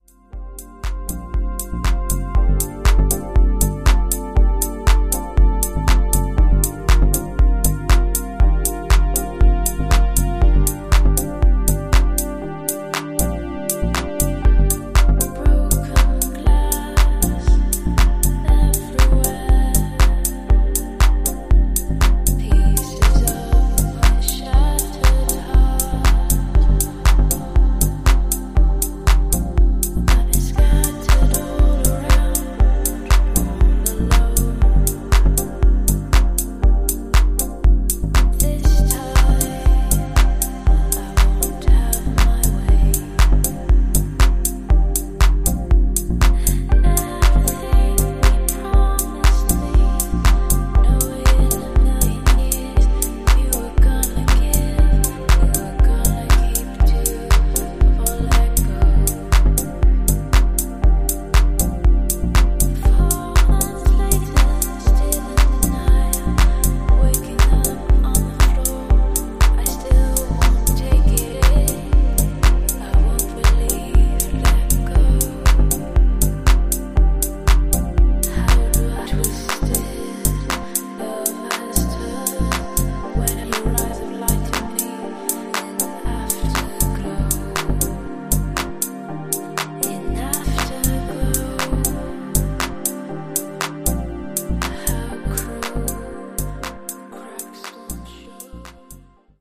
Styl: House Vyd�no